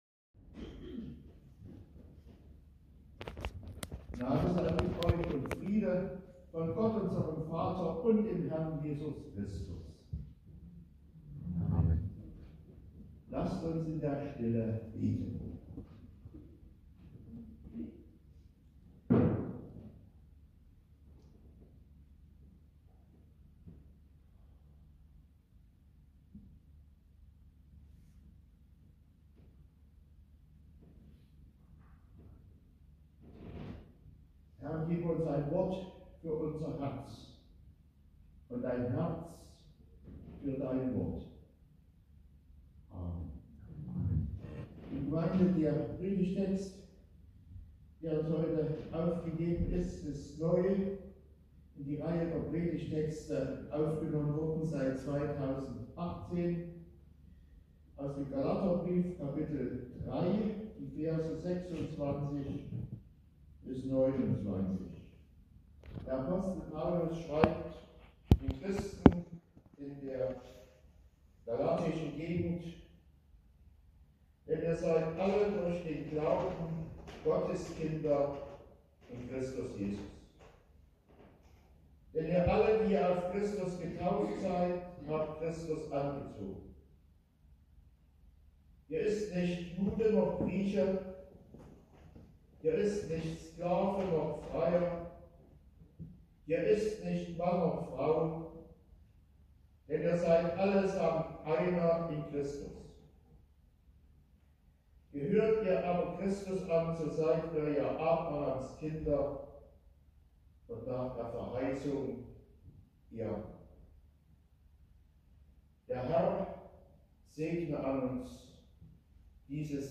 Passage: Galater 3,26-29 Gottesdienstart: Predigtgottesdienst Wildenau « Glaube und Zweifel Es ist gut was Gott geschaffen hat!